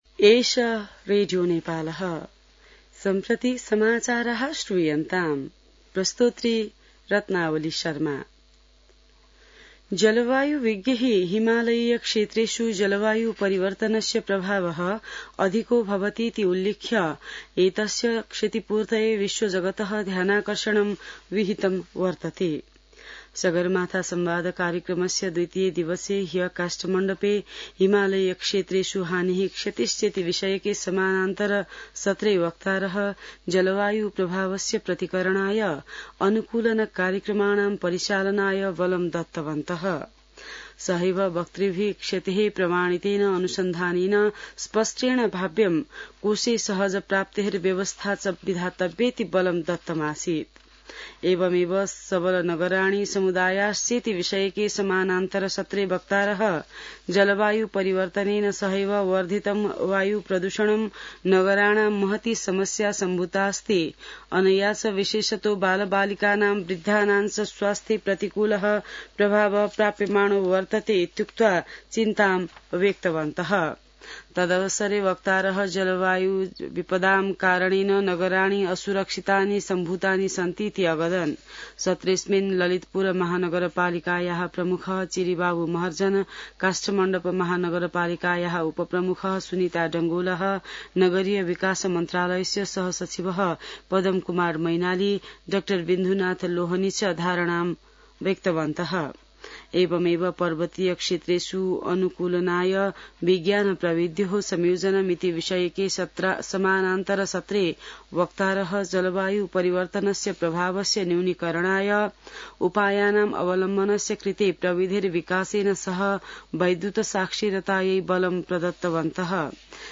संस्कृत समाचार : ४ जेठ , २०८२